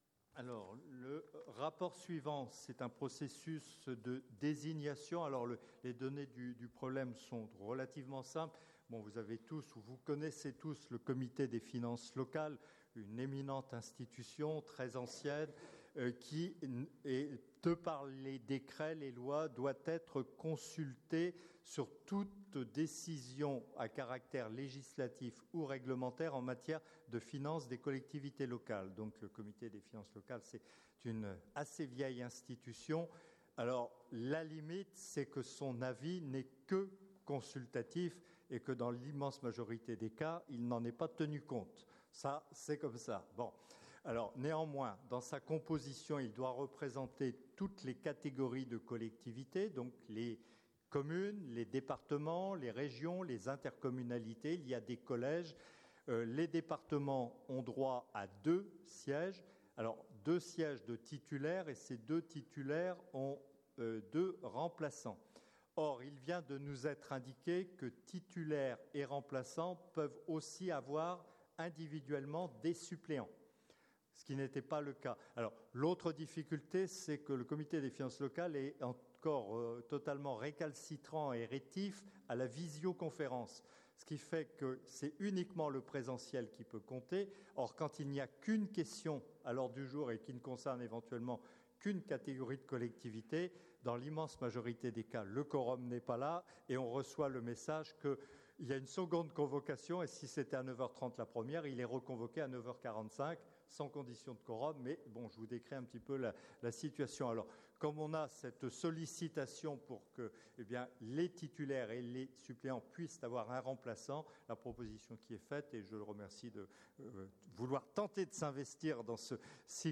• Assemblée départementale du 21/03/24